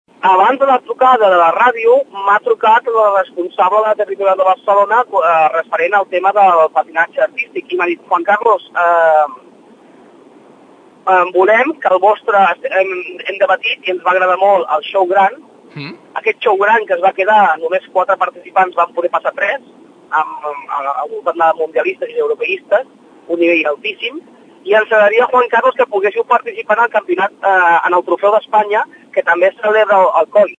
Els van anunciar que l’equip xou gran participaria pel trofeu d’Espanya que també es celebrarà durant les mateixes dates, dins del mateix esdeveniment. Així ens ho explicava en directe